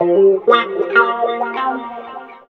90 GTR 3  -L.wav